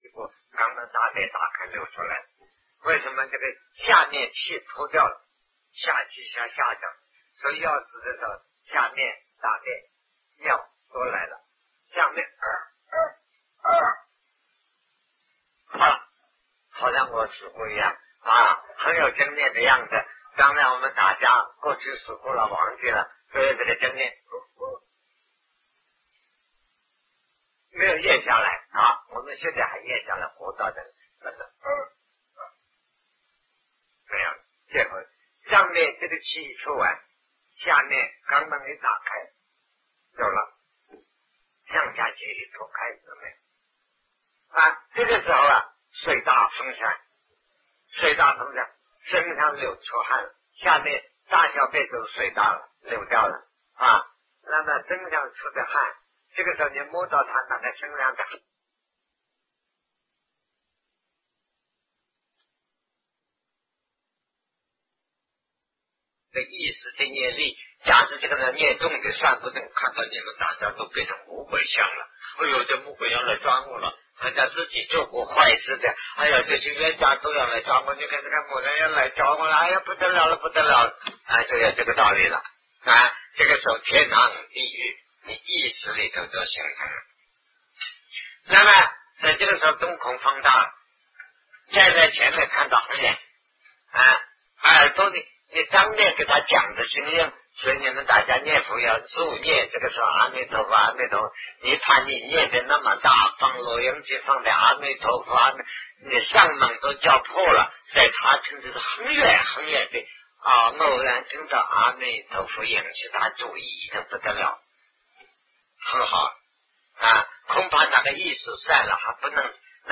阿赖耶识与人死亡的过程 南师讲唯识与中观（1980代初于台湾011(下)